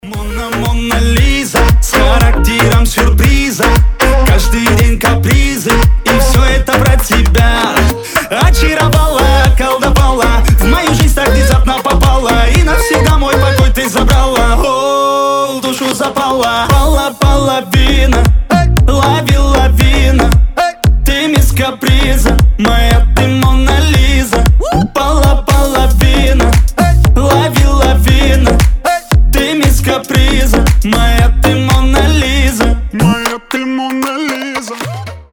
• Качество: 320, Stereo
поп
ритмичные
веселые
басы